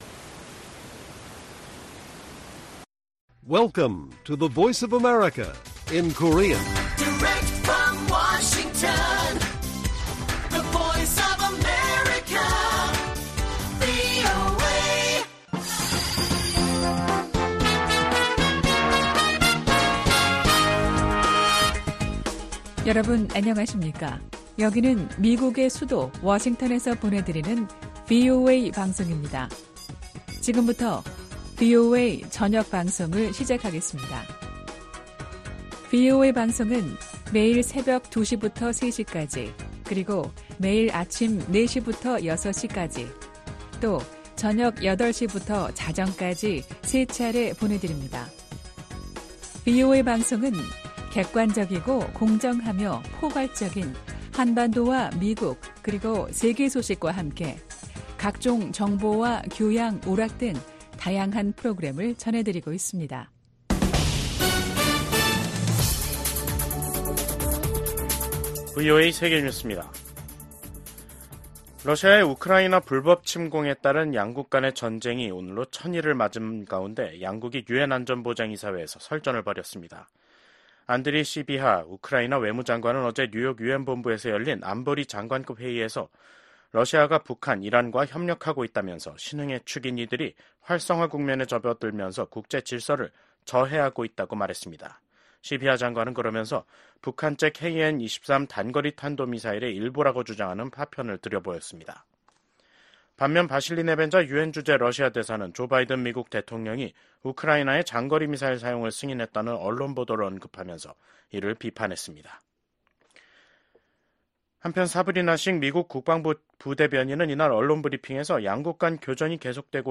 VOA 한국어 간판 뉴스 프로그램 '뉴스 투데이', 2024년 11월 19일 1부 방송입니다. 미국은 러시아가 북한군을 우크라이나 전쟁에 투입해 분쟁을 고조시키고 있다며, 북한군의 추가 파병을 차단하기 위해 중국과 직접 소통하고 있다면서, 단호한 대응 의지를 확인했습니다. 우크라이나 전쟁 발발 1천일을 맞아 열린 유엔 안보리 회의에서 북한군의 러시아 파병과 두 나라 간 군사 협력에 대한 강한 비판이 쏟아졌습니다.